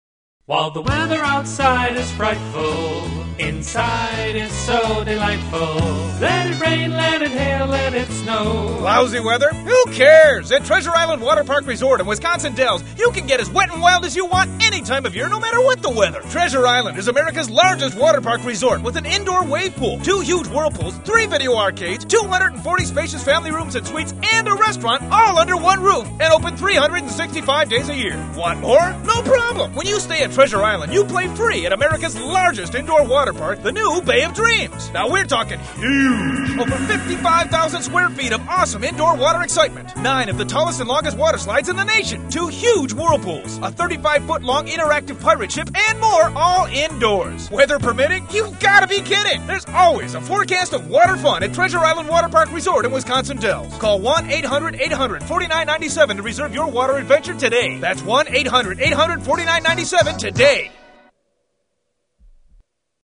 Family Land Radio Commercial